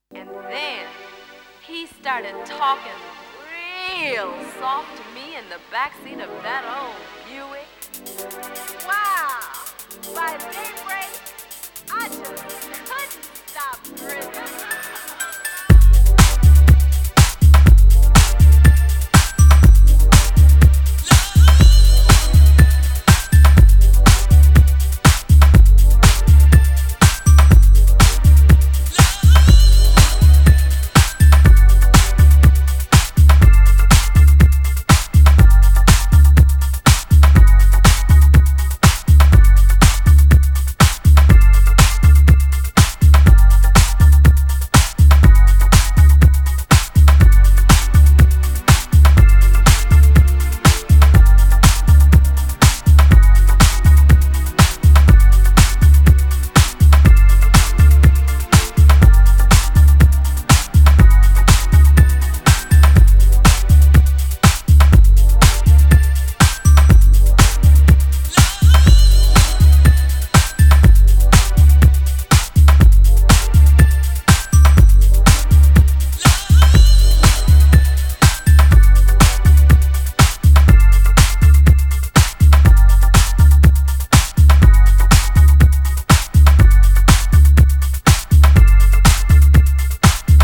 ディープ・ハウスの真髄を突いている、といっても過言では無いでしょう。